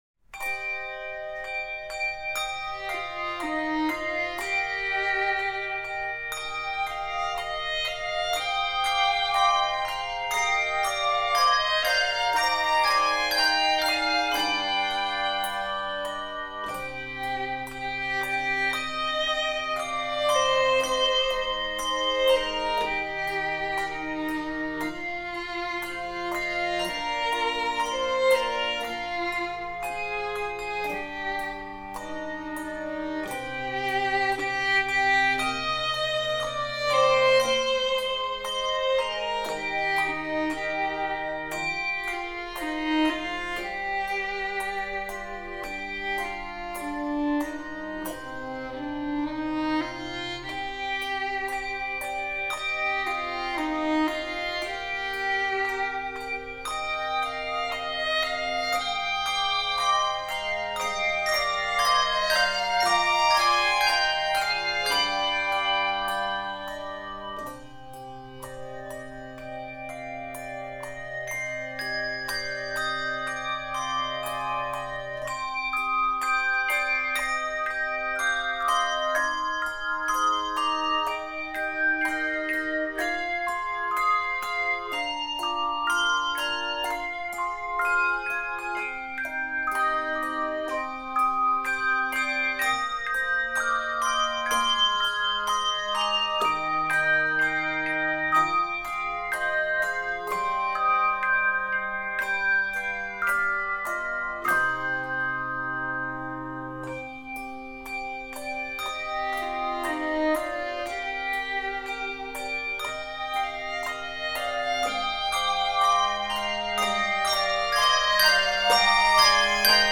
Composer: Finnish Folk Song
Voicing: Handbells 3-5 Octave